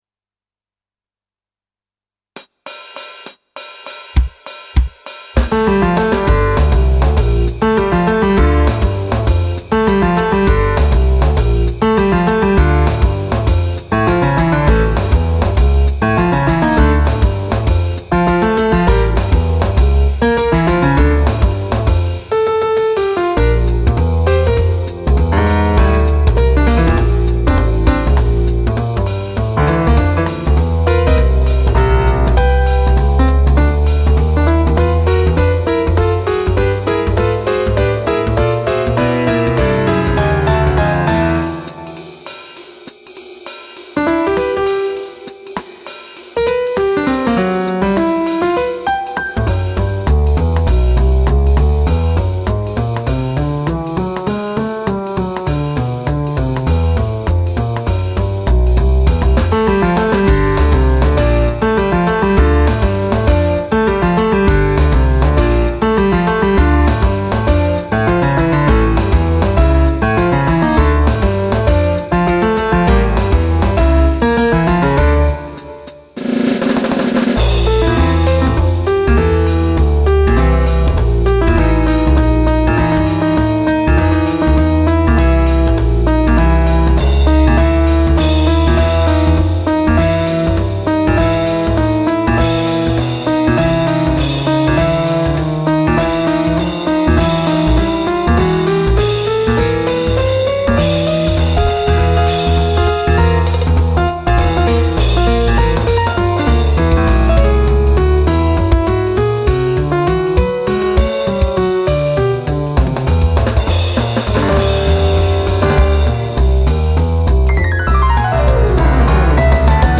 音源は、ＳＣ８８２０を購入したので、さらに良くなっているはず。
思いっきり変拍子のテーマを使ってのJAZZ.。
ちゃんとJAZZ　Pianoをやっている人からみたら、違うって言われると思うけど、テーマはバドパウエル風で、ソロはセロニアスモンク風に。